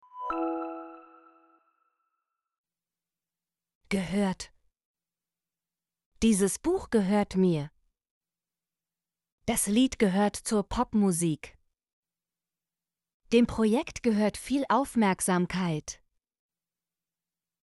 gehört - Example Sentences & Pronunciation, German Frequency List